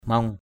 /mɔŋ/